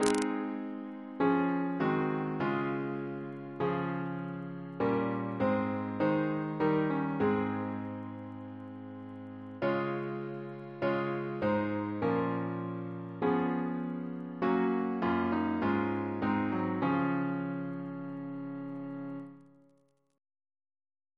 Double chant in D♭ Composer: Garrett C. Wellesley (1735-1781) Reference psalters: ACB: 316